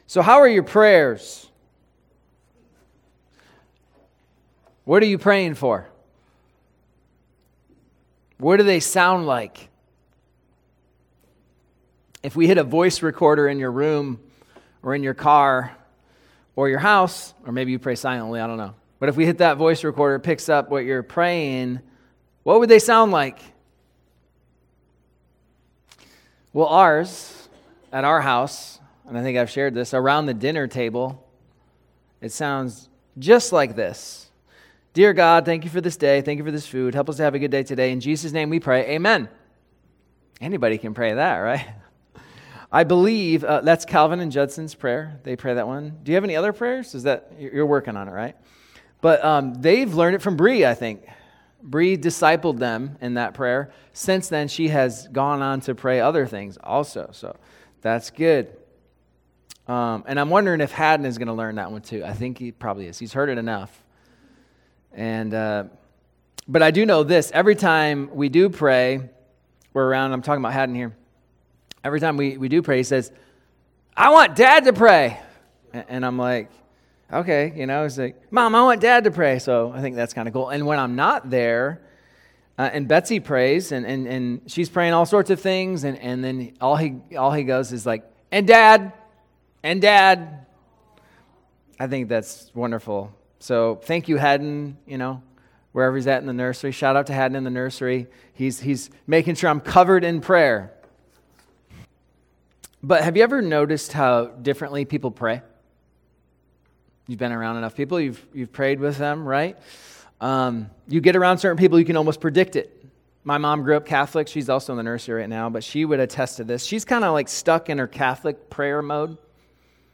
Sermons | Damascus Church